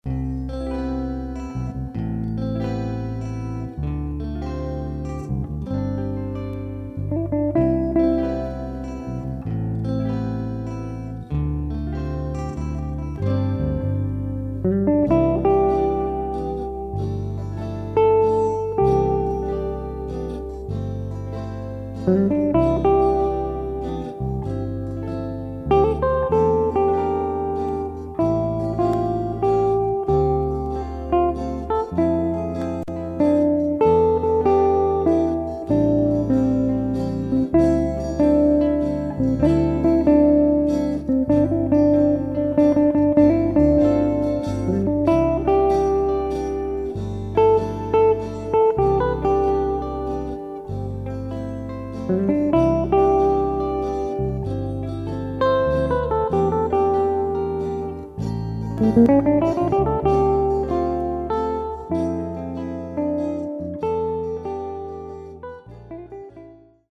Valzer lento.